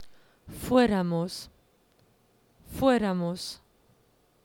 Escucha estas palabras y vuelve a escribir las que necesiten un acento gráfico. Escribe junto a las que no lo necesiten la palabra NO. Vas a escuchar cada palabra dos veces.